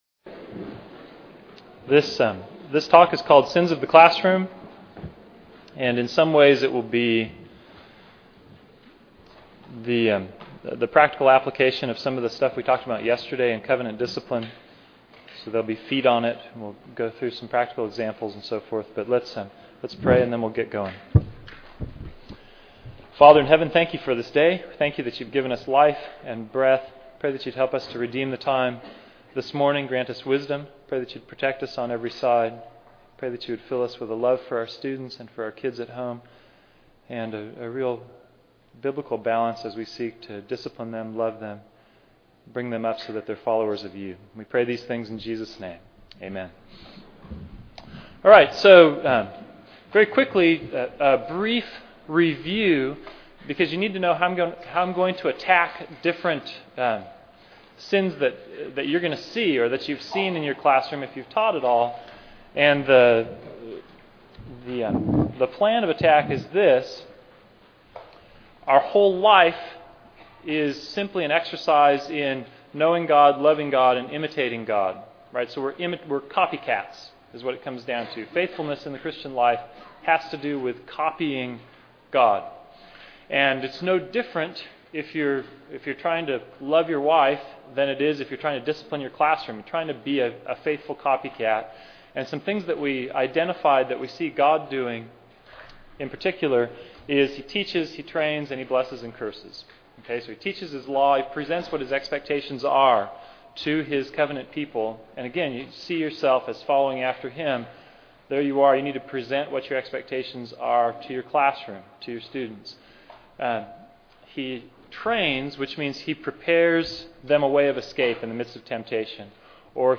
2009 Workshop Talk | 1:05:09 | All Grade Levels, Virtue, Character, Discipline
Mar 11, 2019 | All Grade Levels, Conference Talks, Library, Media_Audio, Virtue, Character, Discipline, Workshop Talk | 0 comments